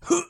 Male Hit 2.wav